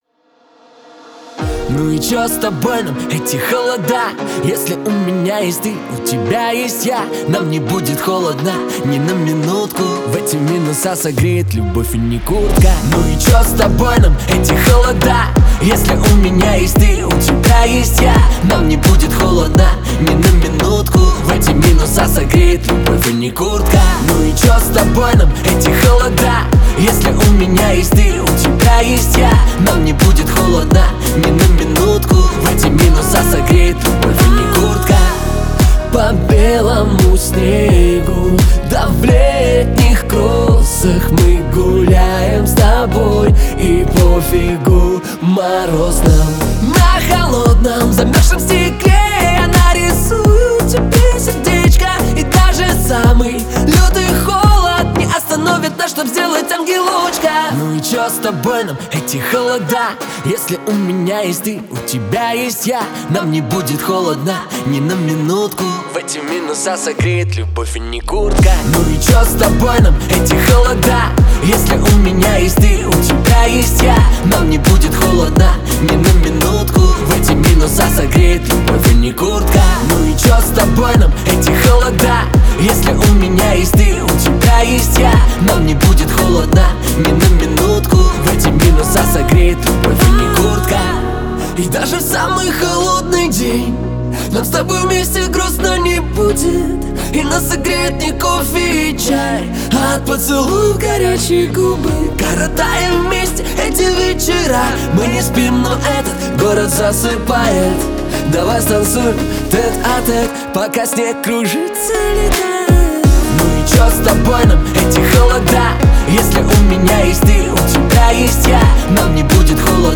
танцевальные песни